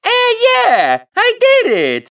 One of Mario's voice clips in Mario Kart 7